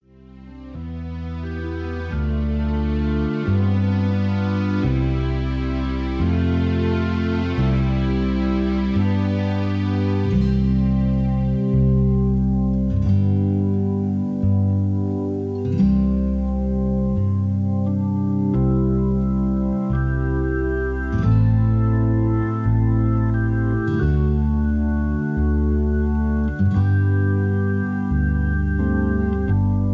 backing tracks
classic rock